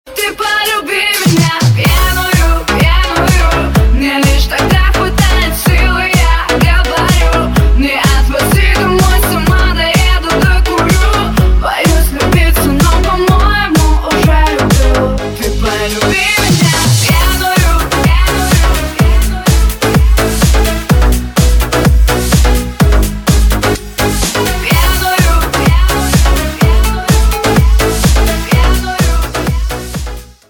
• Качество: 224, Stereo
Стиль: Electro house